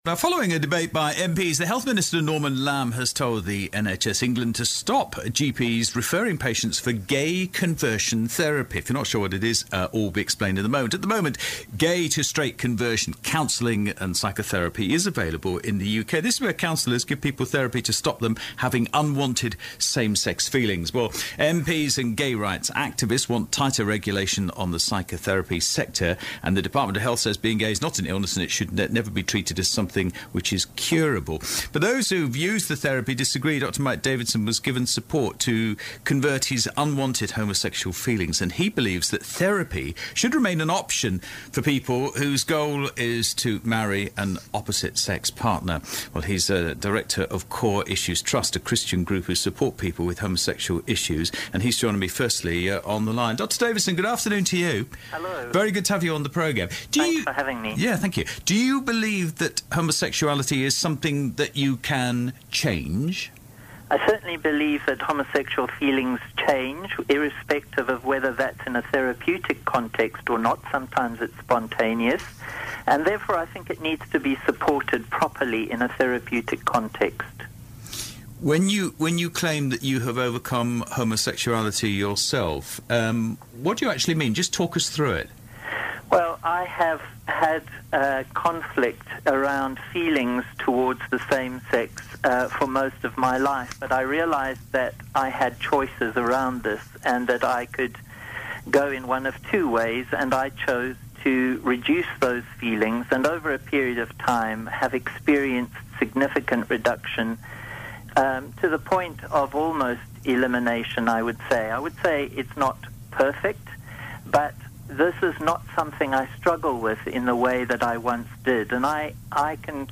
A discussion about therapeutic support for unwanted same-sex attraction - 22nd April 2014